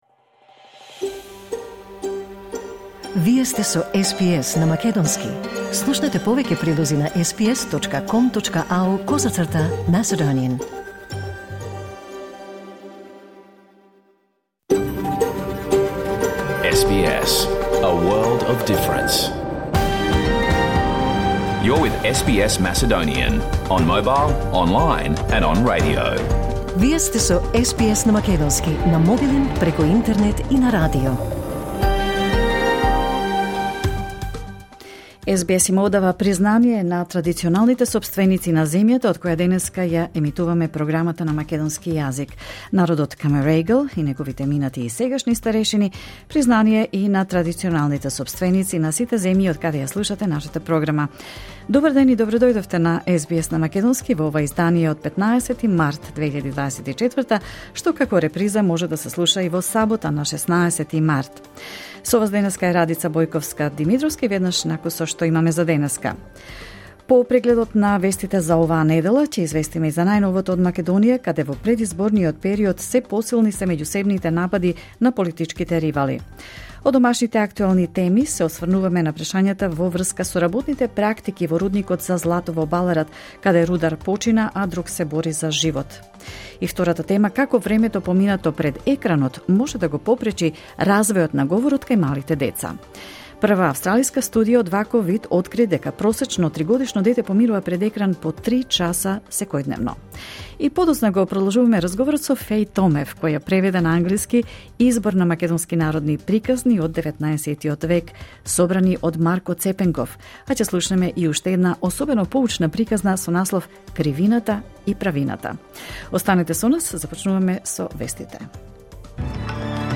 SBS Macedonian Program Live on Air 15 March 2024